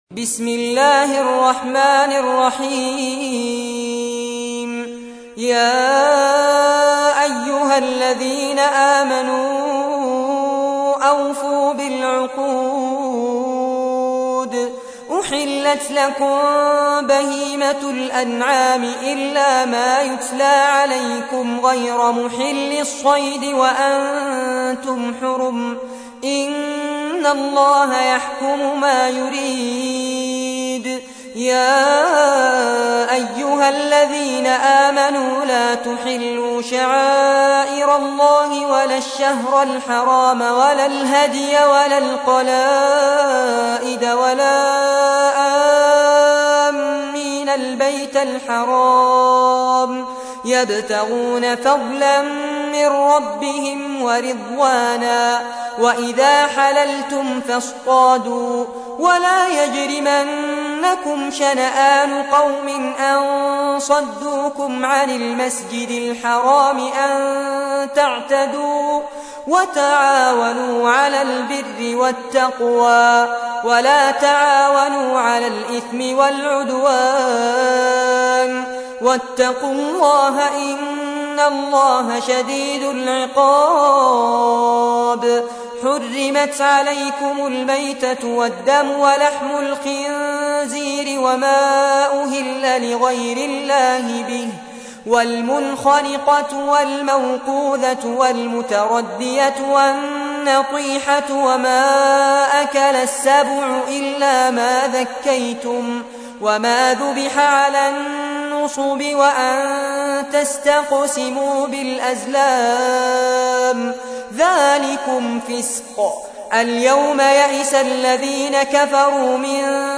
تحميل : 5. سورة المائدة / القارئ فارس عباد / القرآن الكريم / موقع يا حسين